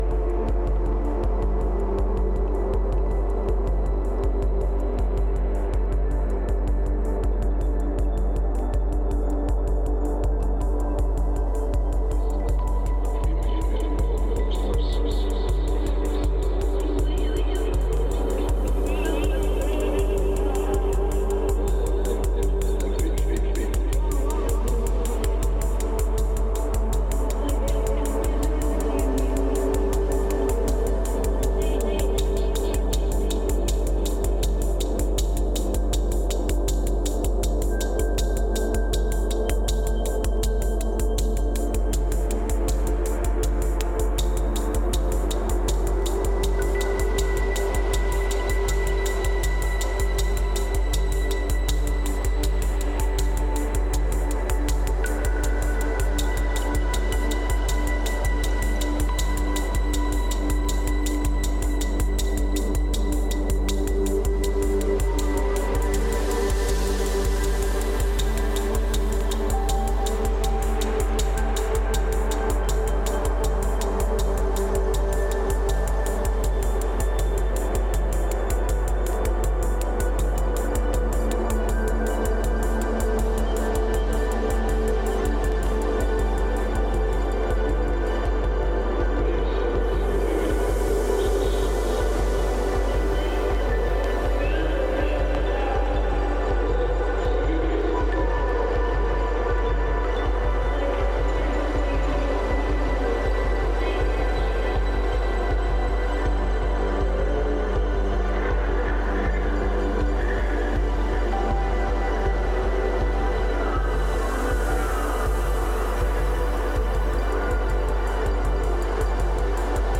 IDM/Electronica
pulsating hypnotic metamorphosis